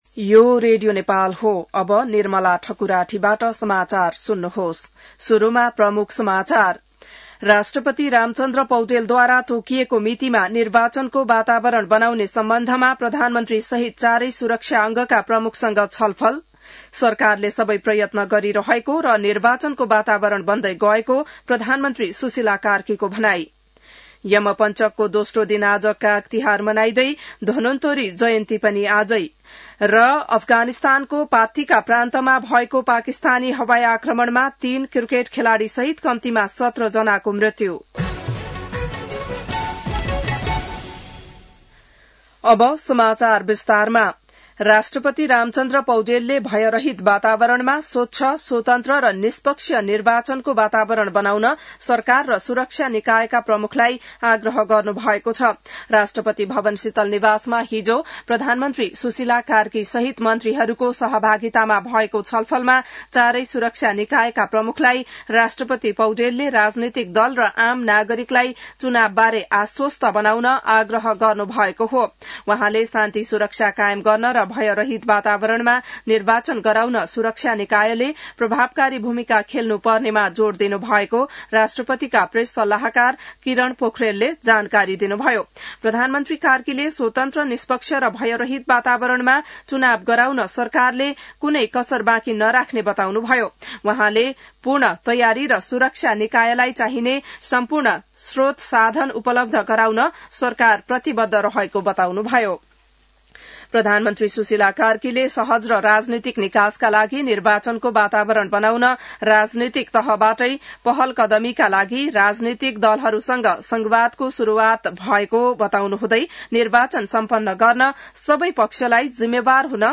बिहान ९ बजेको नेपाली समाचार : २ कार्तिक , २०८२